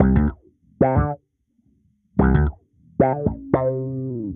Index of /musicradar/dusty-funk-samples/Bass/110bpm